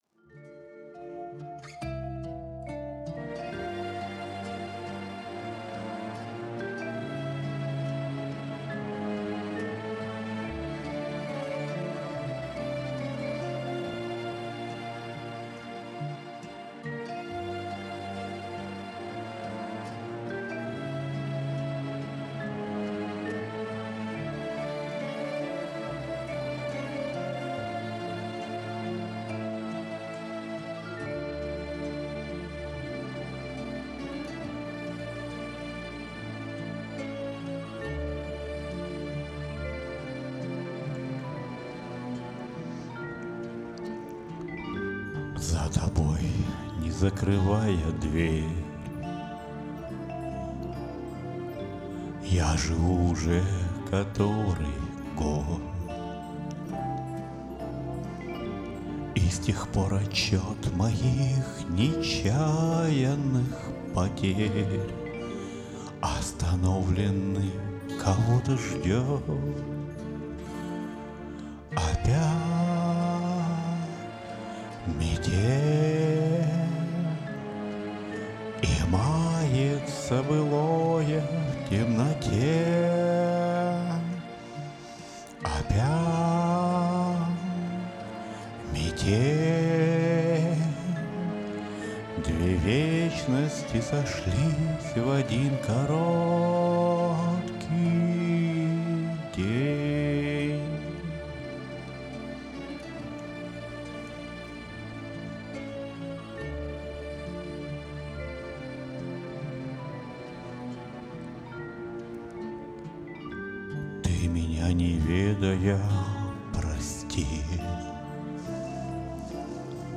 У меня тоже нет ни голоса,ни слуха, а петь охота :)
Очень индивидуально и калоритнo спели.